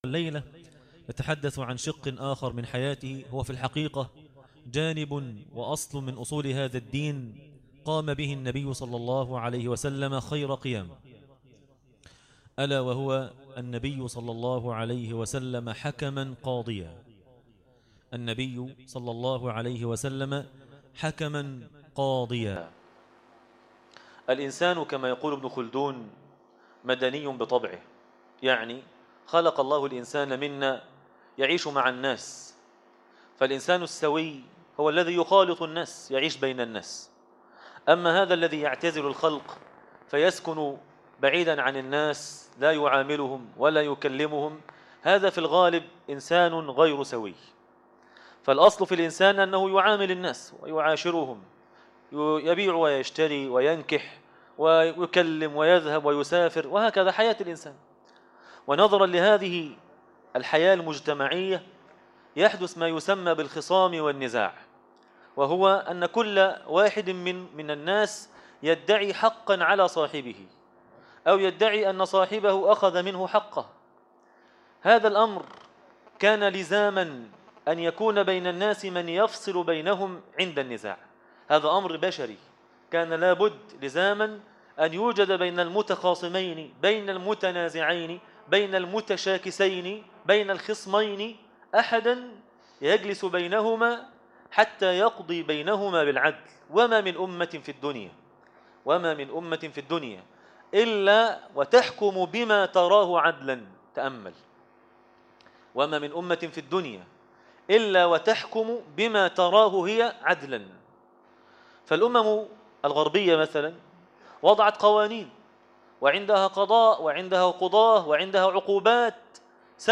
مسجد الإمام البخاري, صلي الله عليه وسلم - النبي حكما قاضيا- درس التراويح ليلة 20 رمضان 1437هـ